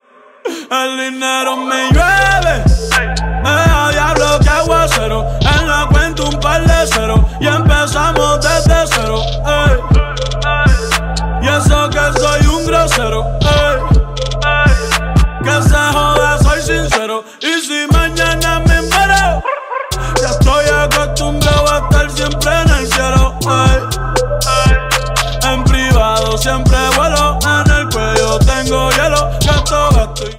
Reguetón